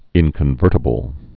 (ĭnkən-vûrtə-bəl)